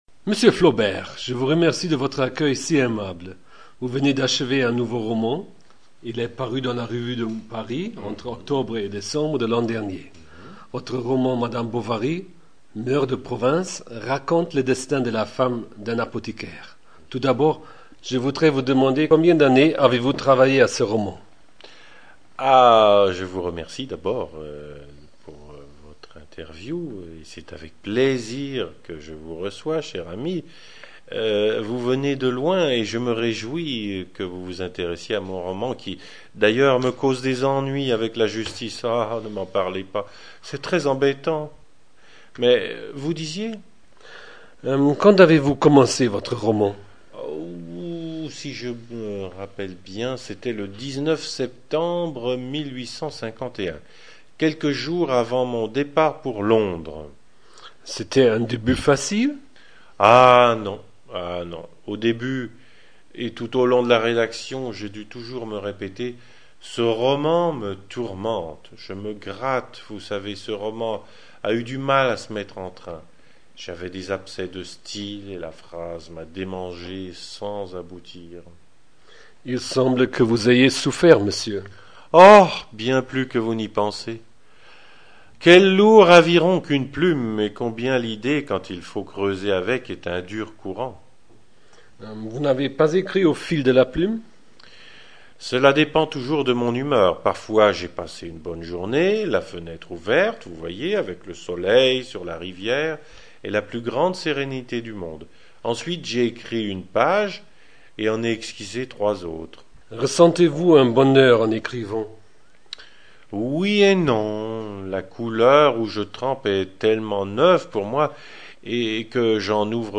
Écoutez l’interview : /wp-content/uploads/2009/06/flaubert.mp3 > le texte de l’interview > La littérature française (II): Gustave Flaubert Le manuscrit de > Madame Bovary est en ligne sur site de Université de Rouen.